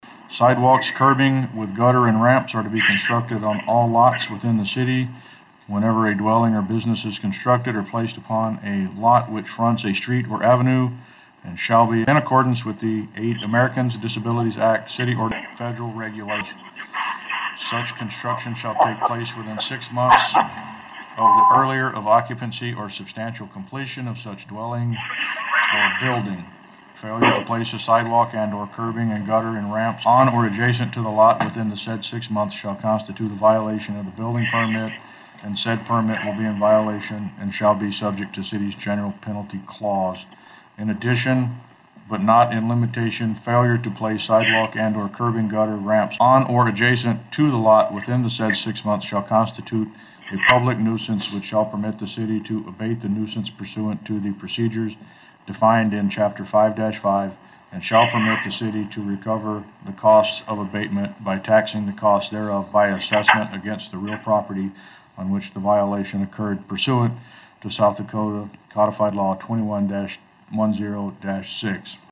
The first reading of an ordinance amending Ordinance 9-6-1 pertaining to construction of sidewalks, curbing, gutter, and ramps was approved.  Mayor Gene Cox read the ordinance.